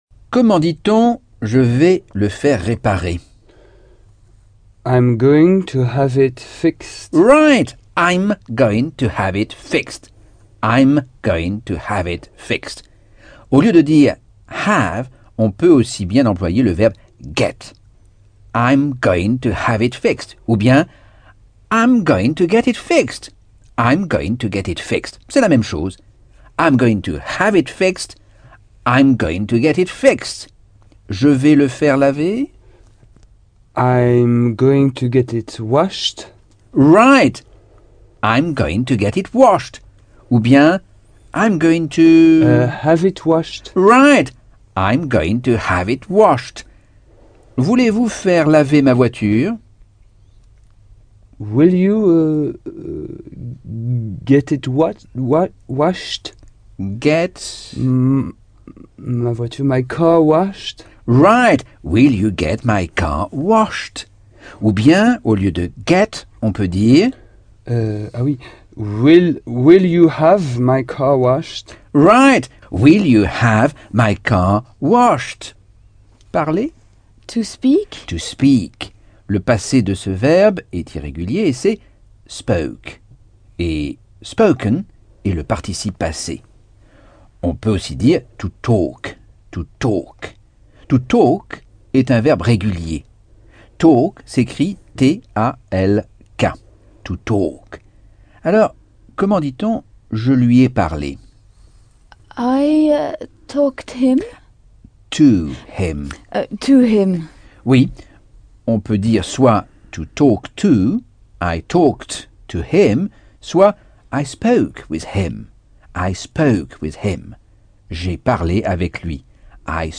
Leçon 5 - Cours audio Anglais par Michel Thomas - Chapitre 11